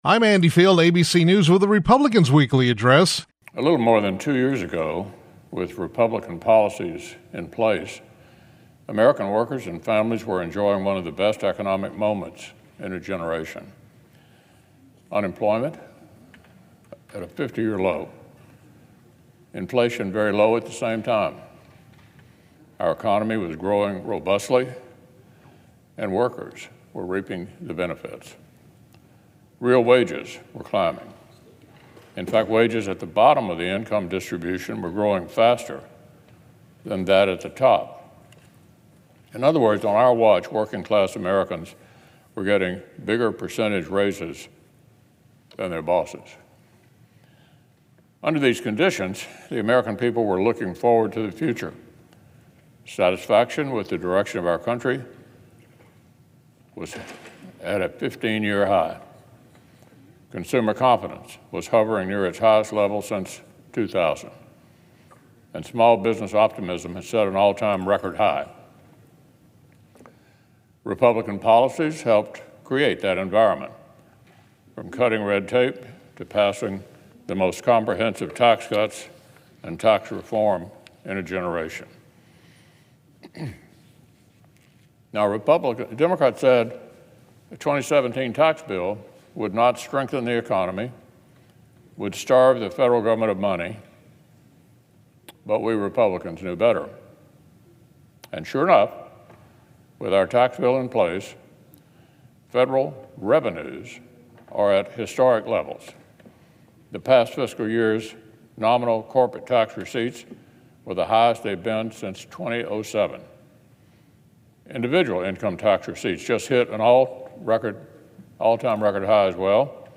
U.S. Senate Republican Leader Mitch McConnell (R-KY) delivered remarks on the Senate floor regarding inflation.